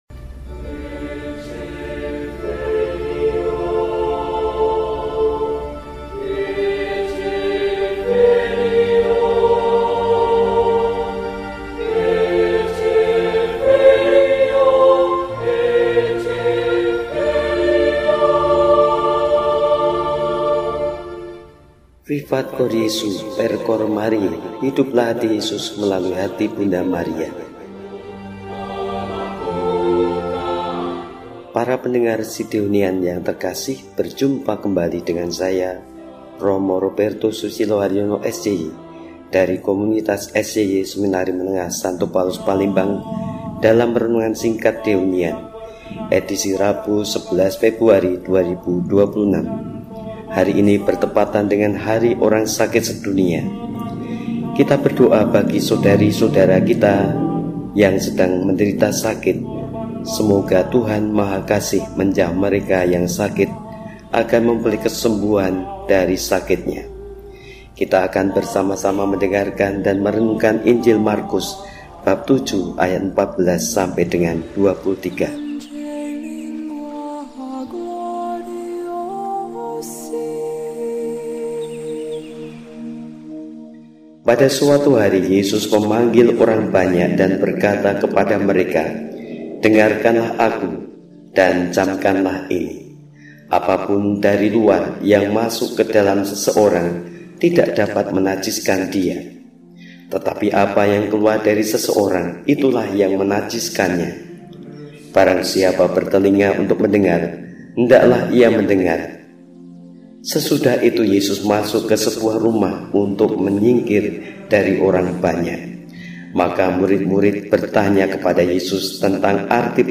Rabu, 11 Februari, 2026 – Hari Biasa Pekan V (Hari Orang Sakit Sedunia ke 33) – RESI (Renungan Singkat) DEHONIAN